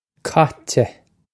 koh-cheh
This is an approximate phonetic pronunciation of the phrase.